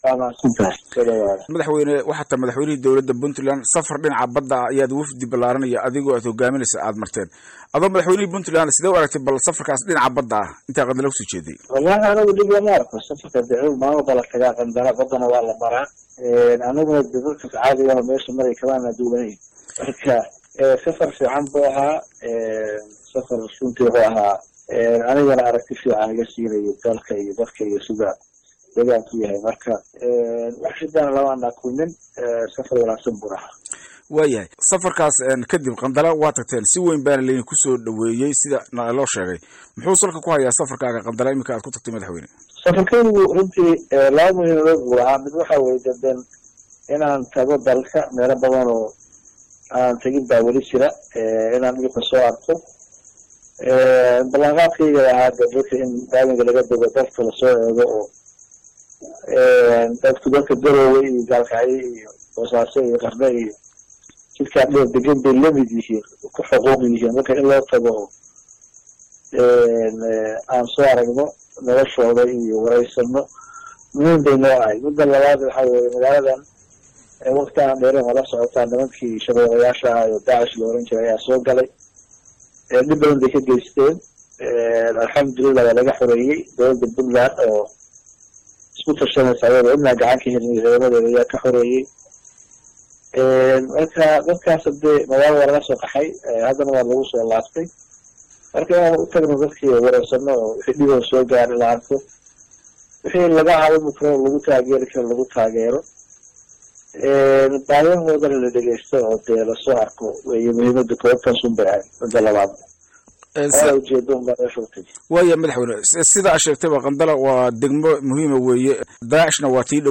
10 jan 2017 (Puntlandes) Madaxweynaha dawladda Puntland Dr. Cabdiweli Maxamed Cali Gaas oo booqasho ku jooga degmada Qandala ee Gobolka Bari, ayaa wareysi uu siiyey Radio Daljir uga hadlay arrimo ay ka mid tahay xaaladda Qandala.
Dhagayso Madaxweyne Gaas oo Radio Daljir waraystay